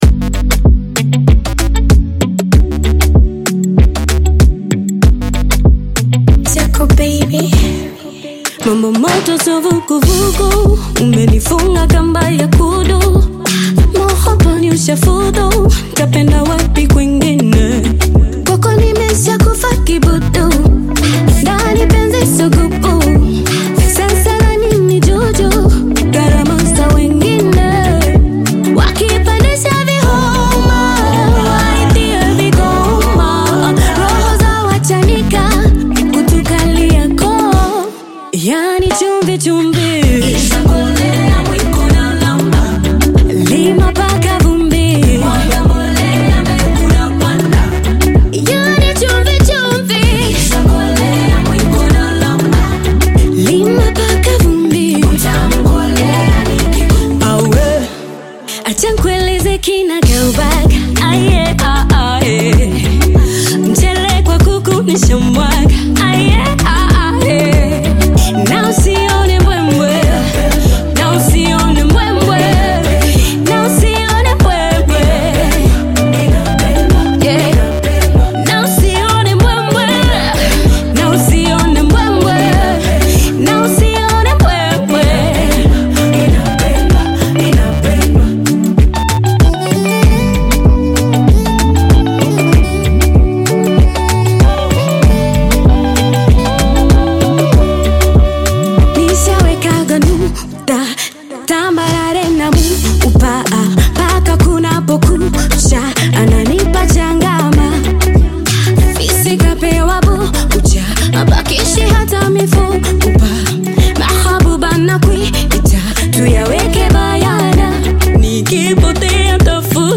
bongo flava
female artists
African Music